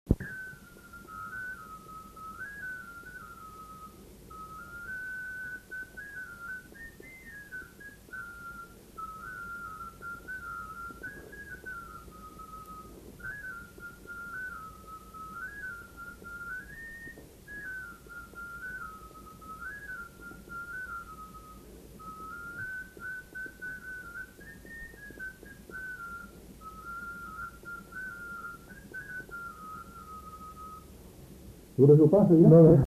joué à la flûte de Pan et à l'harmonica